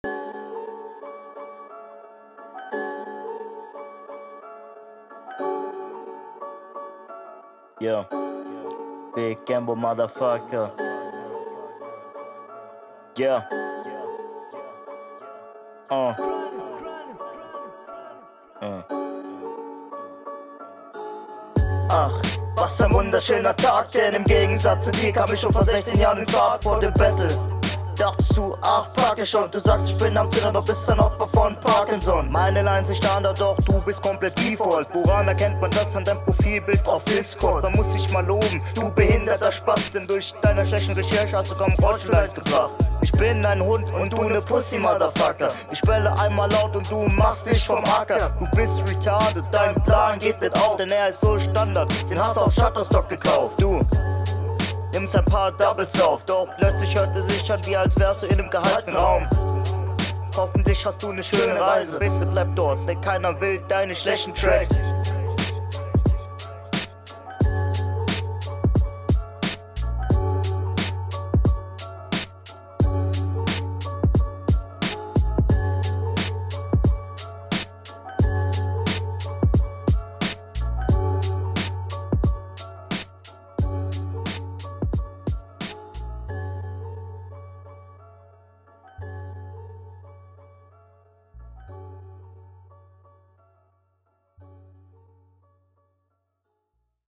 Auf dem Beat kommst du leider nicht wirklich gut klar, hier haben wir auch wieder …
Flow: Du solltest die schnellen Stellen vermeiden.